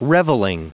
Prononciation du mot revelling en anglais (fichier audio)
Prononciation du mot : revelling